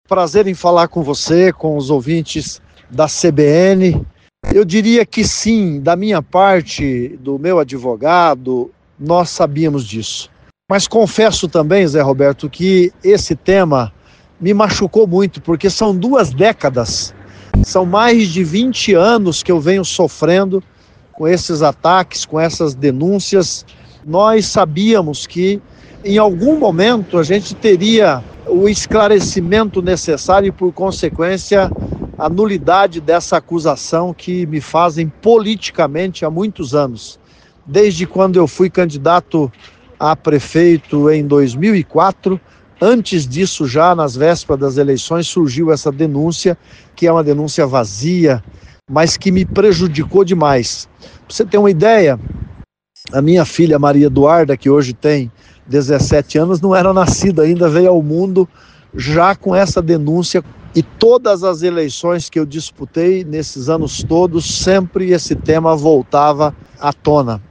Em entrevista à CBN Cascavel logo após a decisão do TRF-4, na tarde desta terça-feira, o ex-prefeito Paranhos comentou sobre a vitória alcançada no Tribunal.
Player Ouça Paranhos, ex-prefeito de Cascavel